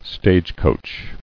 [stage·coach]